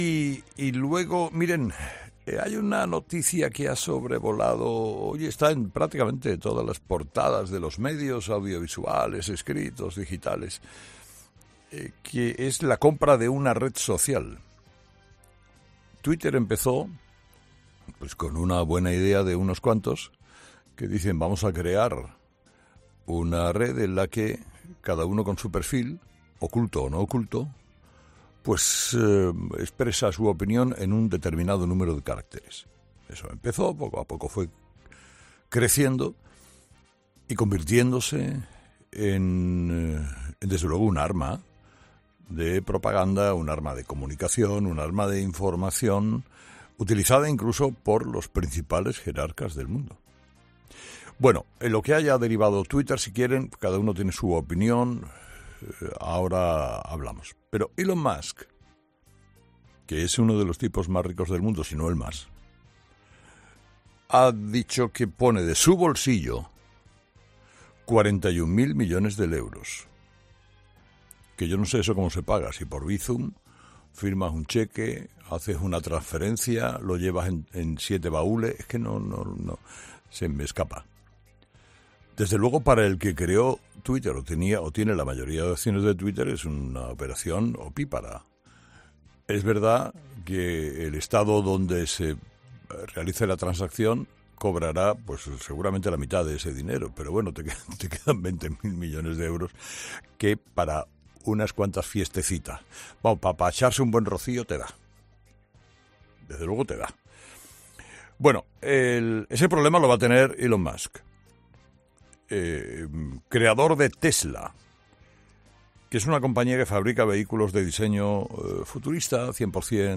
El presentador de 'Herrera en COPE' analiza el movimiento empresarial que ha realizado Elon Musk para hacerse con la red social más importante en el debate político